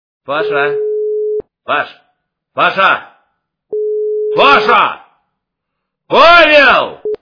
» Звуки » Именные звонки » Именной звонок для Павлу - Паша.